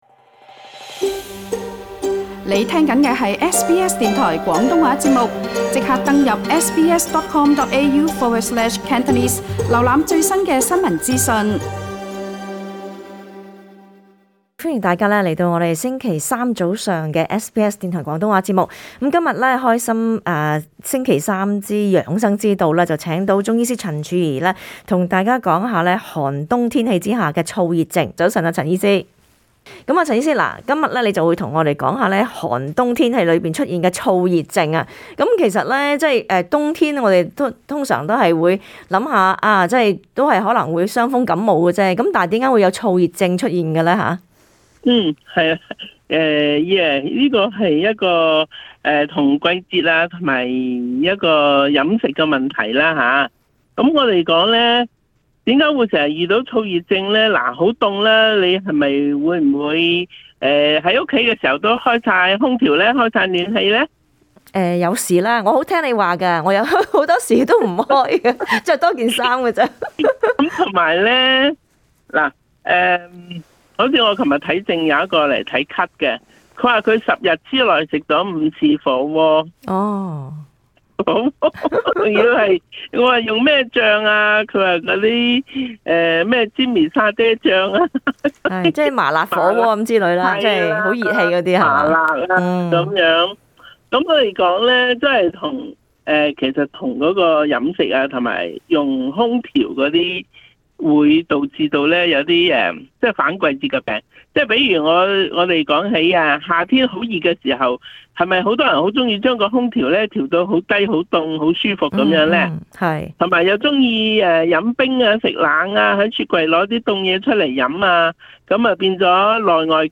* 本節目 內嘉賓及聽 眾意見並不代表本台立場， 而所提供的資訊亦只可以用作參考，個別實際情況需要親自向有關方面 查詢為準 。